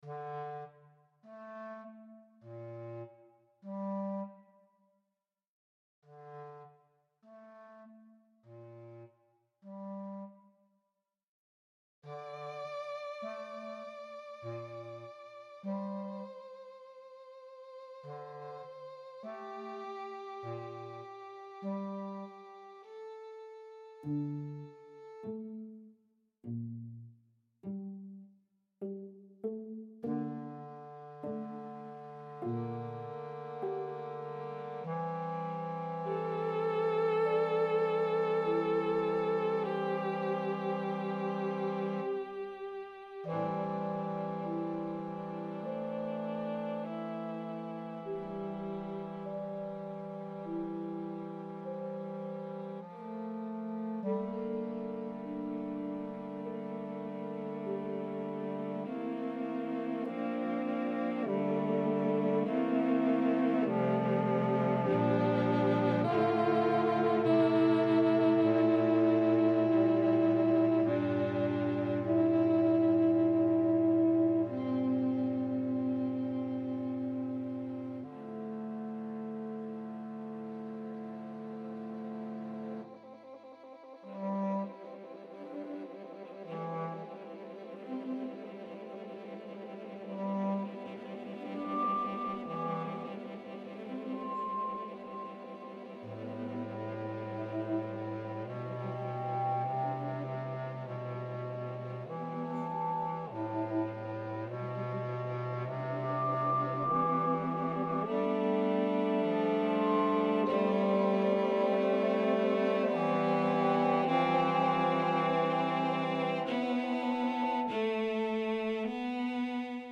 But I recently upgraded significantly to SWAM instruments. Much less lush but more controllable, this piece was the first I wanted to do because of it's intimate feeling, and use of overtones and unusual techniques..
"Walk to forget Bass Clarinet, Alto Saxophone, 1 Percussion, Violin, Viola, Violoncello.
In this context, I imagine something legato and sostenuto, non-triatic and lacking “home”.
A meditative ending."